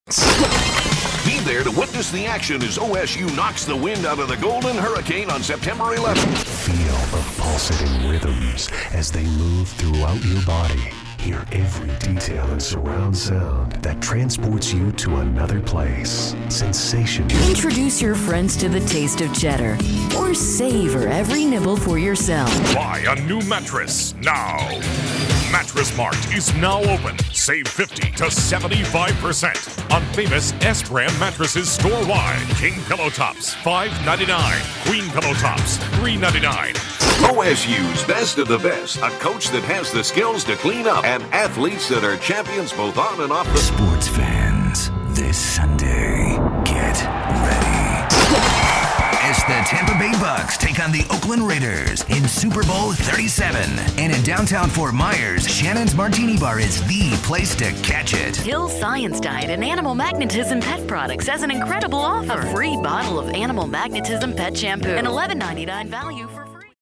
Commercial Demo
commercialdemo.mp3